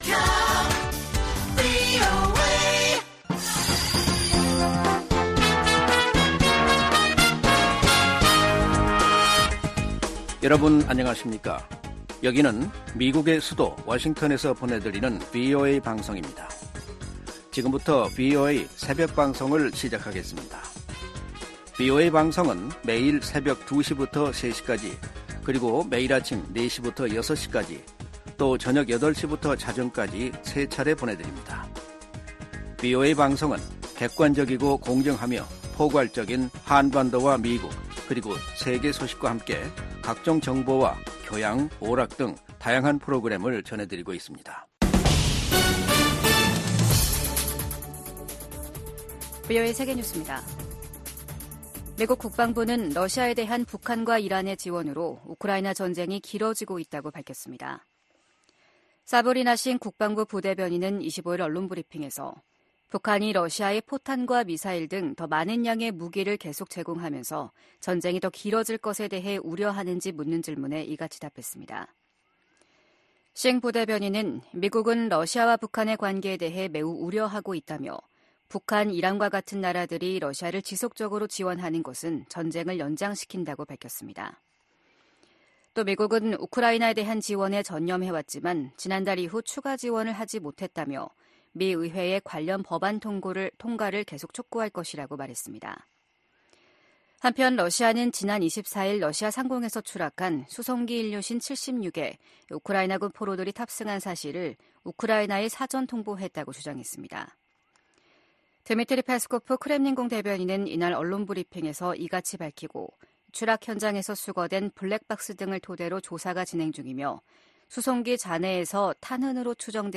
VOA 한국어 '출발 뉴스 쇼', 2024년 1월 27일 방송입니다. 제네바 군축회의에서 미국과 한국 등이 북한의 대러시아 무기 지원을 규탄했습니다. 미 국방부는 북한의 대러시아 무기 지원이 우크라이나 침략 전쟁을 장기화한다고 비판했습니다.